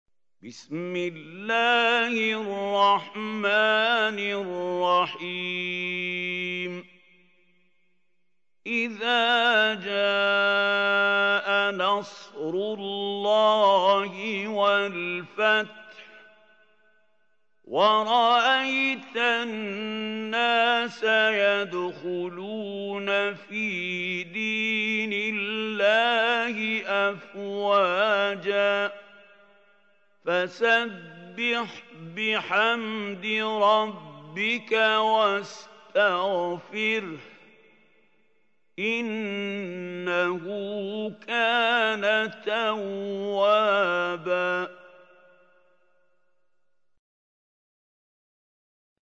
سورة النصر | القارئ محمود خليل الحصري